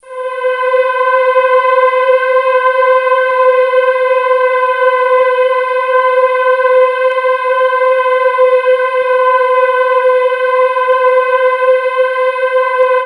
STRINGLOW -R.wav